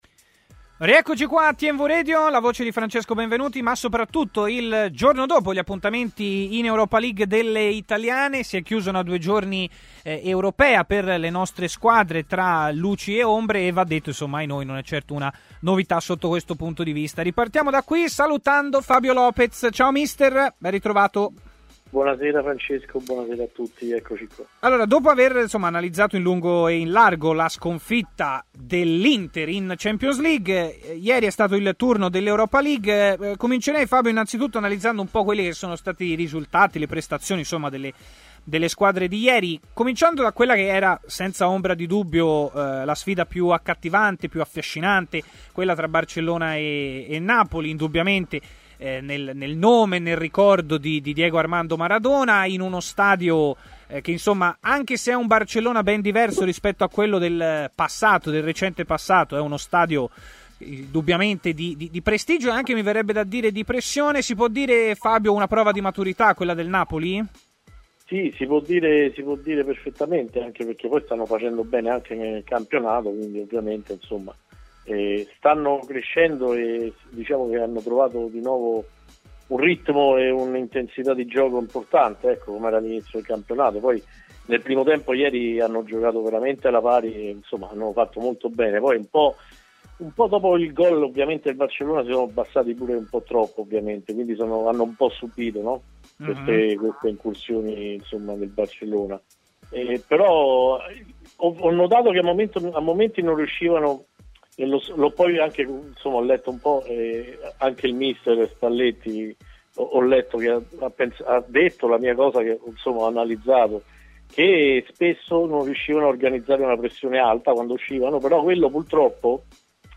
ha parlato a Stadio Aperto, trasmissione di TMW Radio